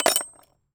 metal_small_movement_06.wav